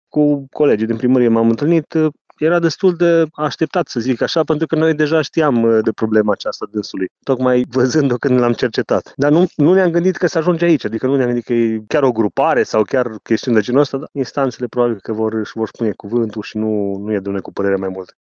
Edilul din Ineu spune că până și cei care îi cunoșteau scrierile au rămas surprinși să-l găsească pe Radu Theodoru în cazul deschis de DIICOT.